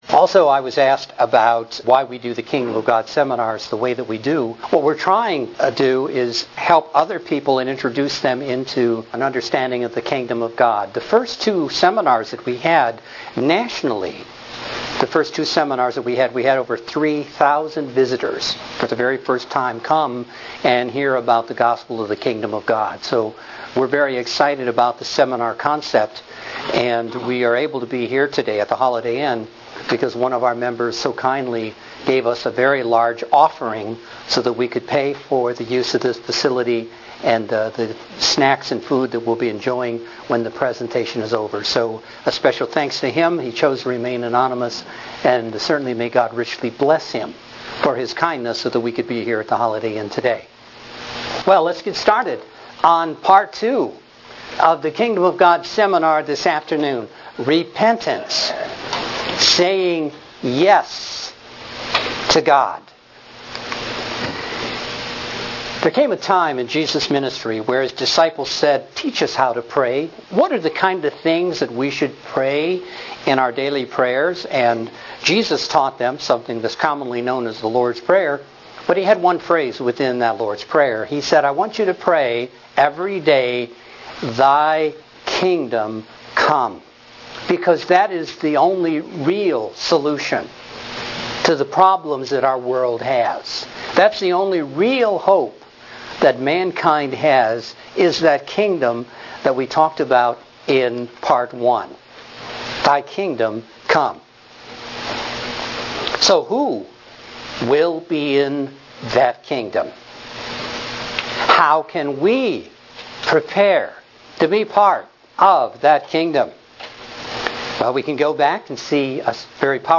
How can we prepare to be in that Kingdom? This Kingdom of God seminar discusses the need for repentance and how it is central to answering these questions.
UCG Sermon Transcript This transcript was generated by AI and may contain errors.